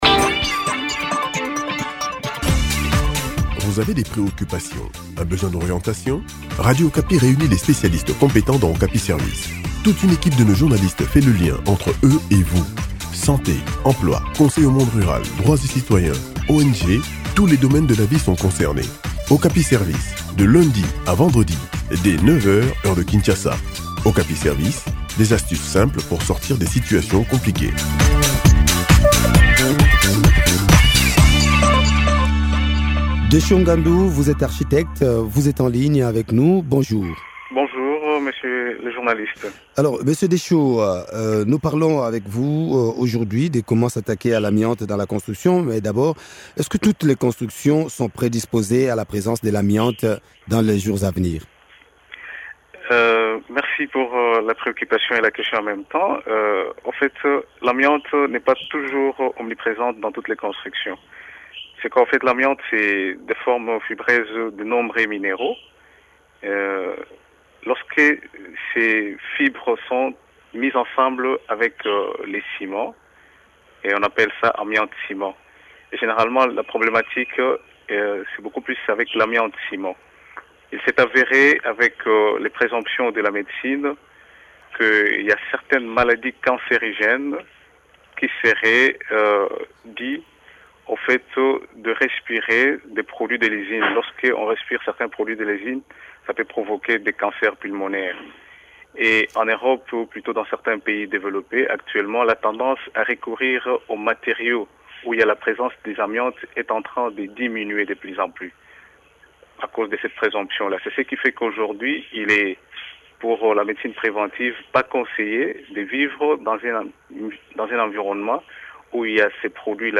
Les éléments de réponse dans cet entretien